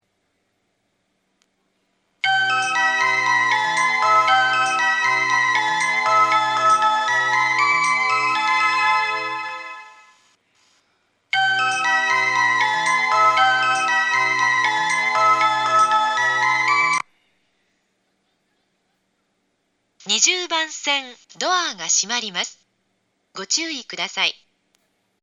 発車メロディ 曲名は『新たな季節』です。
1.8コーラスです。
日中でも余韻までは鳴りやすいです。
時間に余裕があるので2コーラス目にも入りやすいです。
omiya-20_melody.mp3